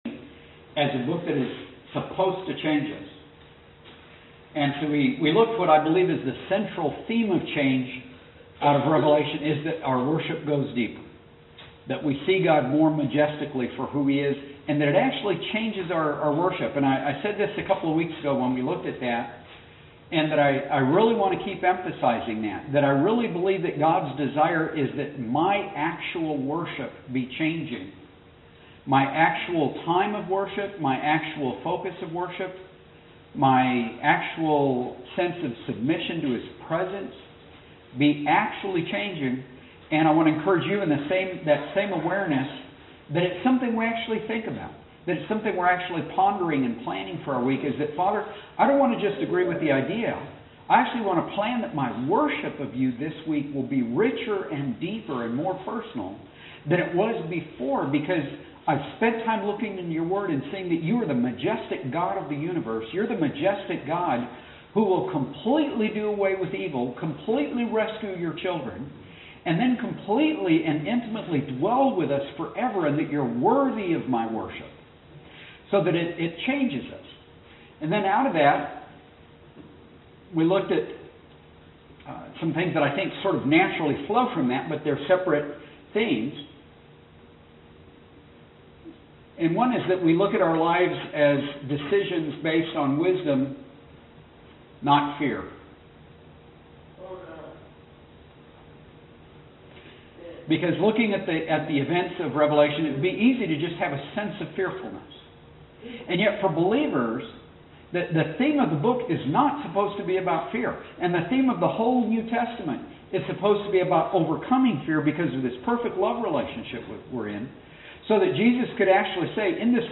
Yes – the the recording starts part way through the sermon, and the recording is very different than usual.